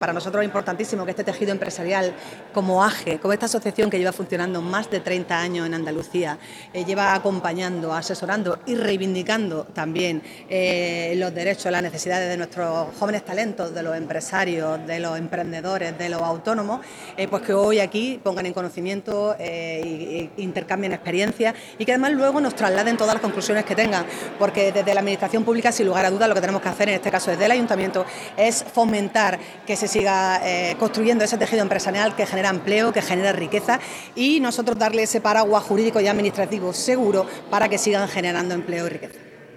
AJE Andalucía celebra su Comité Ejecutivo Andaluz en la Capilla del MUREC de Almería - Blog Diputación de Almería
30-01_aje_alcaldesa.mp3.mp3